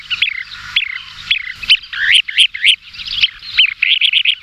Gravelot à collier interrompu, charadrius alexandrinus